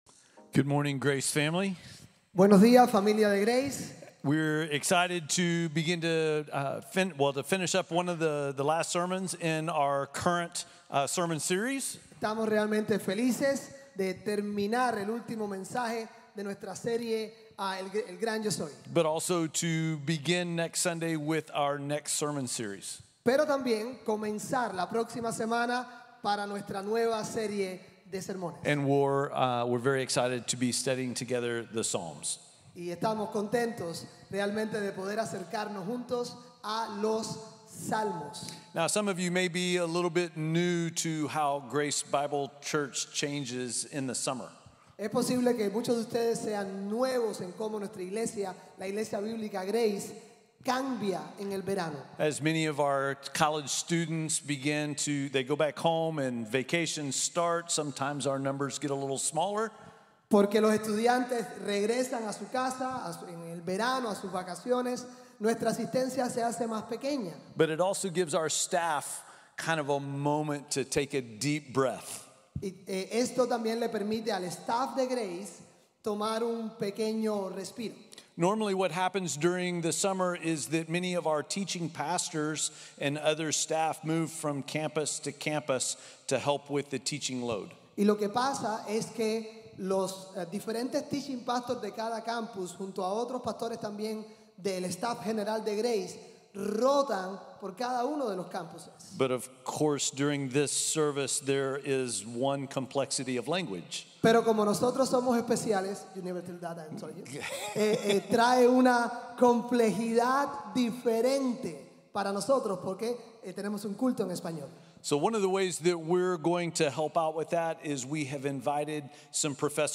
Yo soy la vid verdadera | Sermon | Grace Bible Church